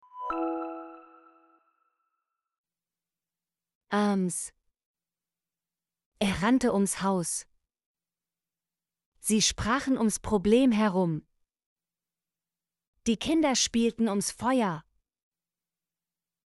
ums - Example Sentences & Pronunciation, German Frequency List